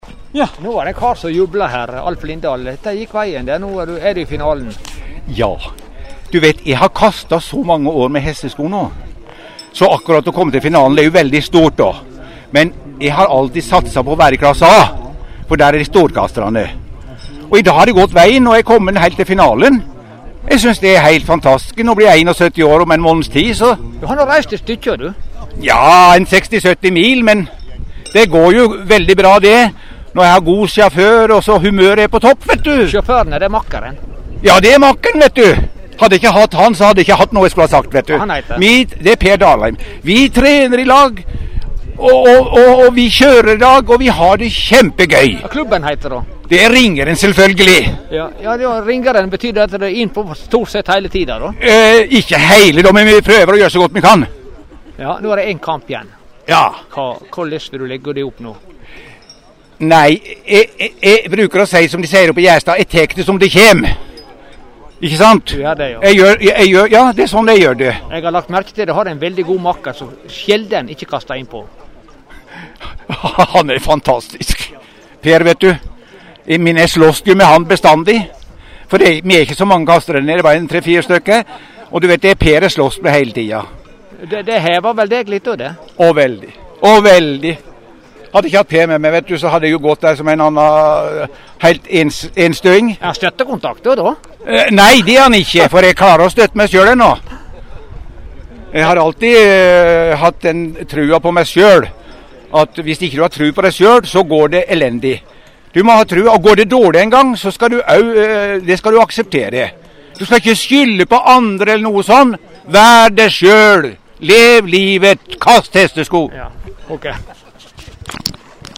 under NM på Breim 2008.